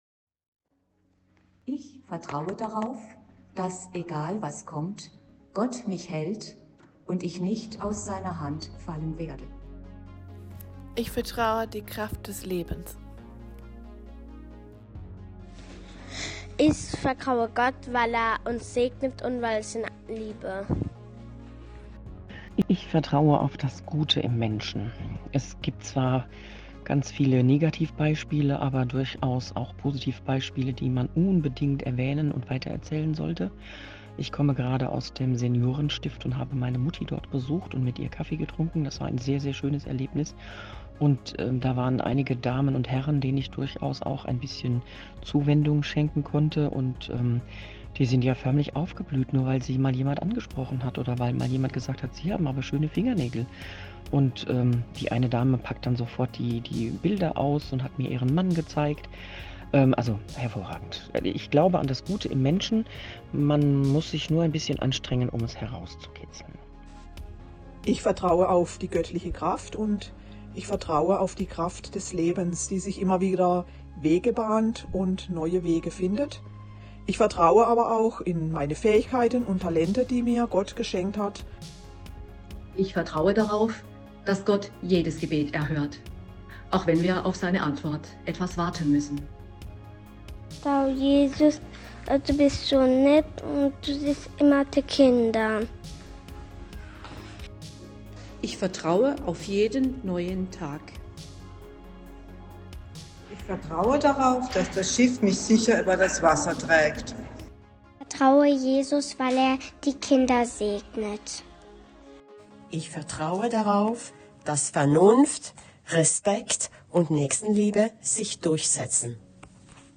Wir haben die Community und Menschen aus Lörrach in Süddeutschland danach gefragt, worauf sie vertrauen. In Zeiten von Misstrauen braucht es Erzählräume, in denen wir unser Vertrauen teilen und stärken.